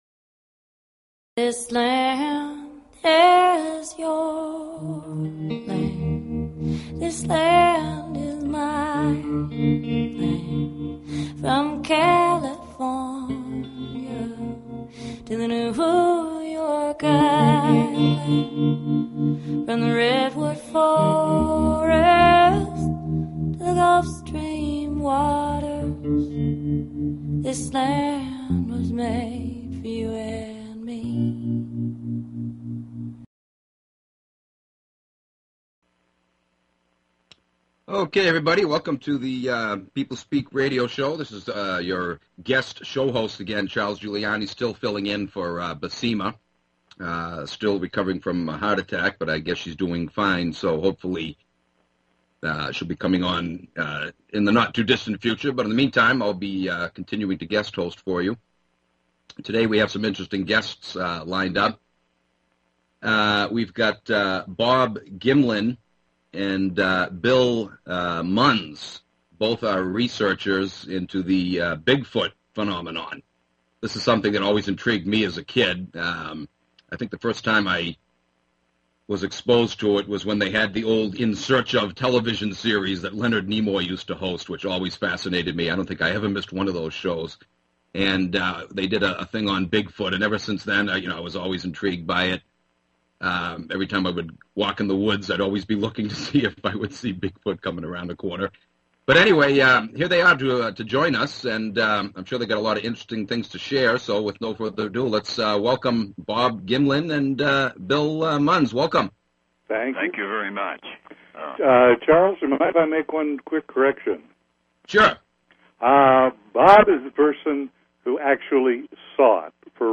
The show features a guest interview from any number of realms of interest (entertainment, science, philosophy, healing, spirituality, activism, politics, literature, etc.).
The radio show name, The People Speak, is based on the idea of allowing our audience - the People - a chance to interact with the guests during the hour, and we take phone or text questions from them during the interview.